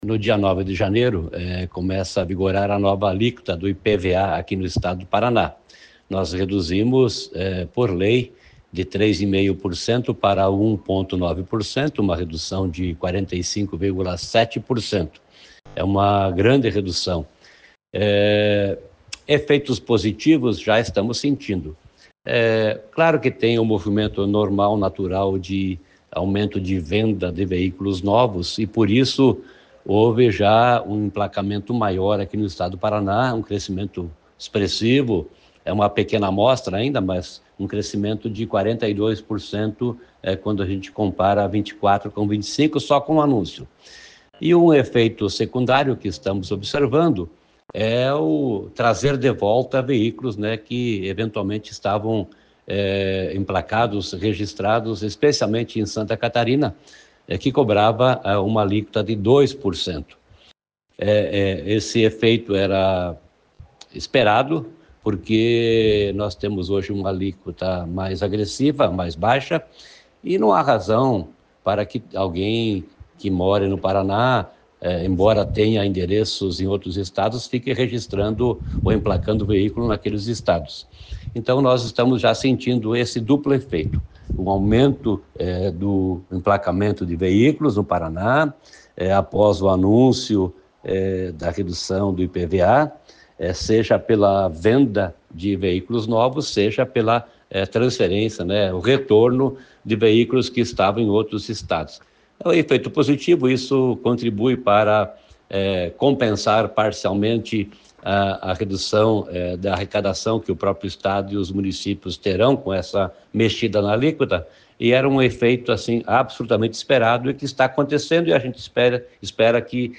Sonora do secretário da Fazenda, Norberto Ortigara, sobre o aumento de emplacamentos com a redução do IPVA